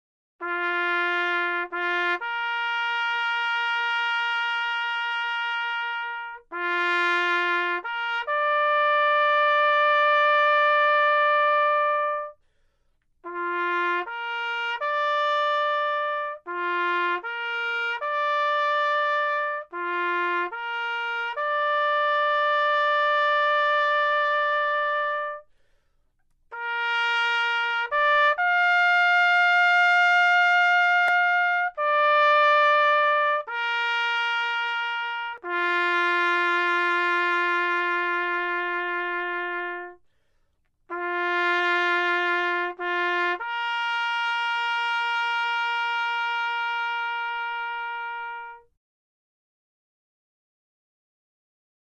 Navy Bugle Calls